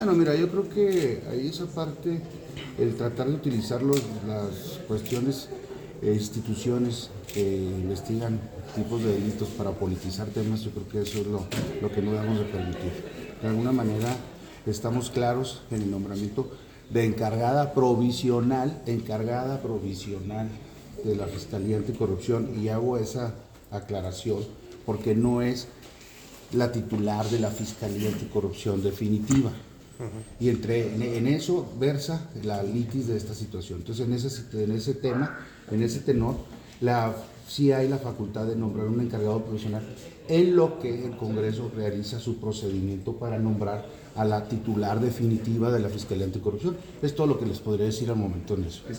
Interrogado sobre su parecer de esta situación, el fiscal general Roberto Fierro Duarte manifestó que, para él, se trata de un intento de politizar las instituciones encargadas de investigar este tipo de delitos.